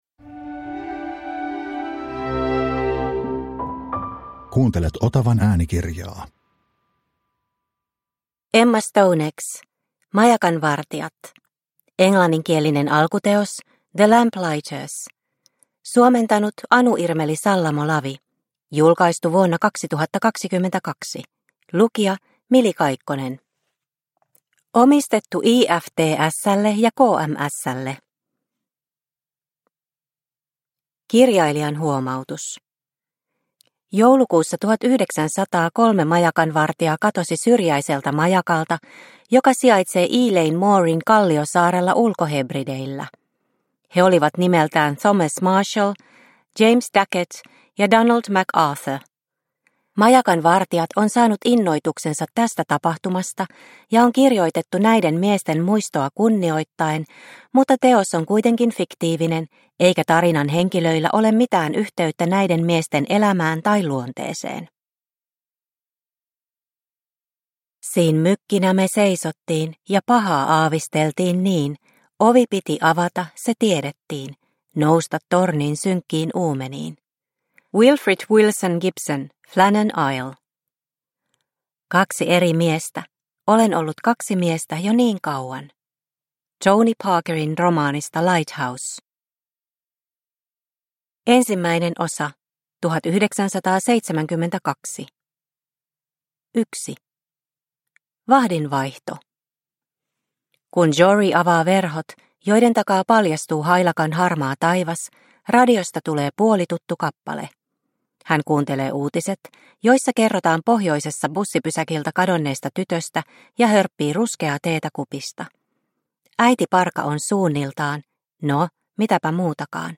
Majakanvartijat – Ljudbok – Laddas ner